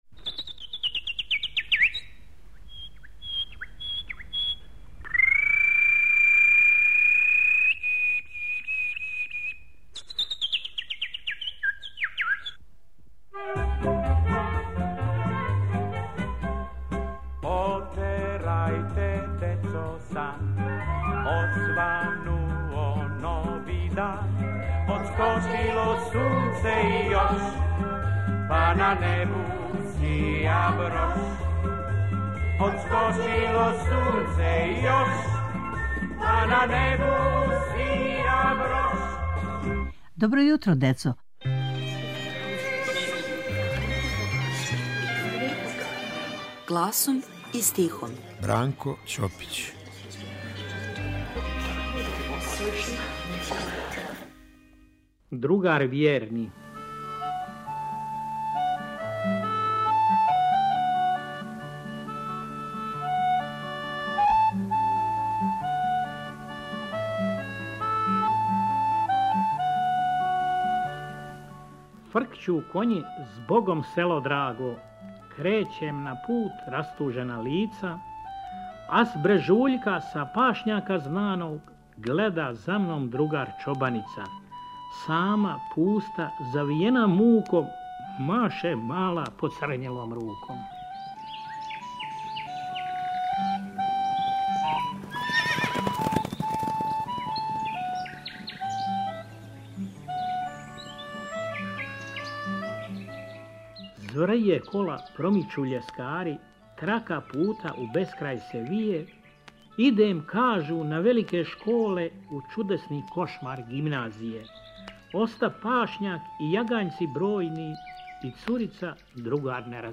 У серијалу "Гласом и стихом" - из старе фиоке архиве Радио Београда, гласом и стихом, јављају се чувени песници за децу.
Ово је јединствена прилика за слушаоце јер су у питању аутентични звучни записи које само Радио Београд чува у својој архиви. Ове недеље - Бранко Ћопић.